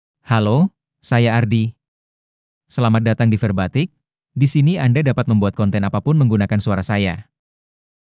ArdiMale Indonesian AI voice
Ardi is a male AI voice for Indonesian (Indonesia).
Voice sample
Listen to Ardi's male Indonesian voice.
Male